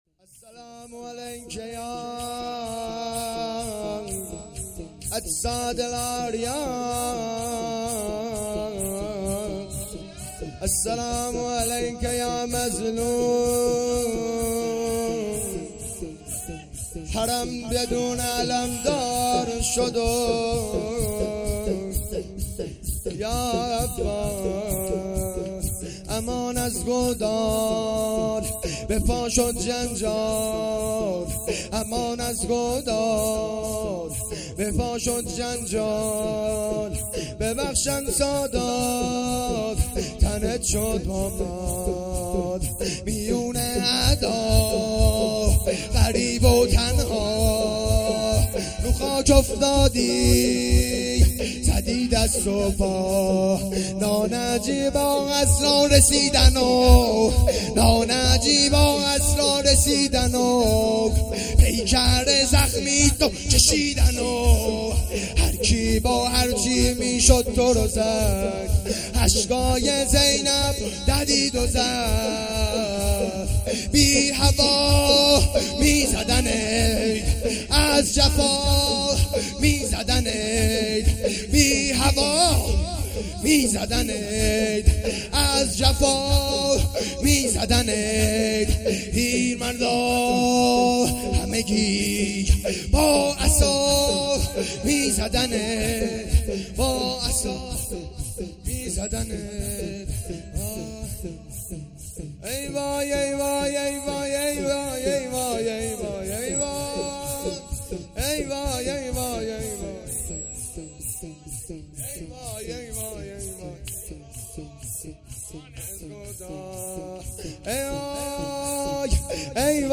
شور
شب نهم محرم الحرام ۱۴۴۳